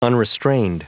Prononciation du mot unrestrained en anglais (fichier audio)